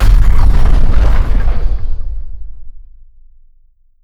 GroundSlam.wav